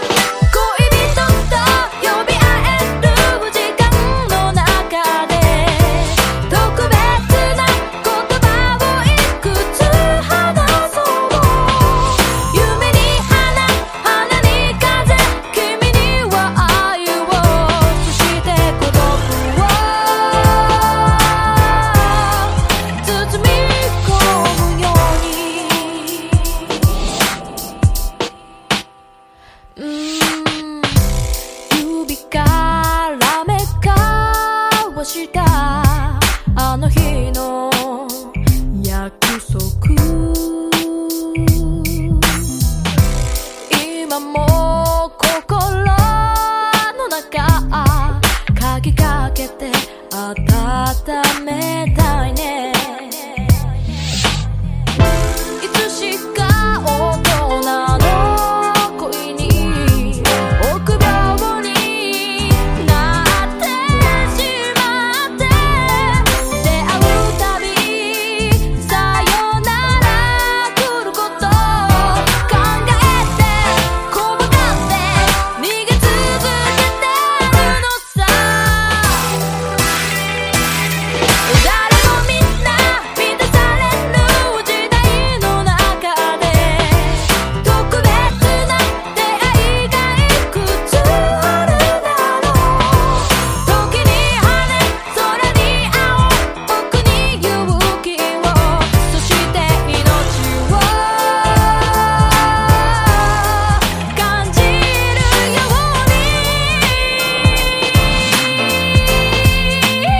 国産90’S R&B最重要曲！